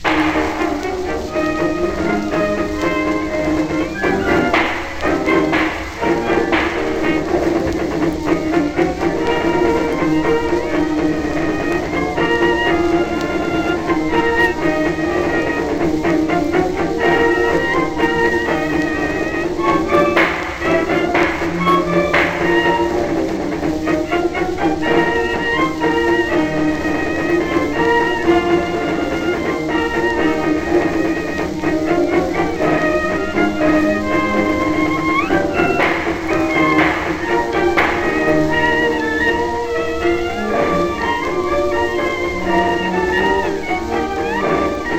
Jazz, Ragtime, New Orleans　USA　12inchレコード　33rpm　Mono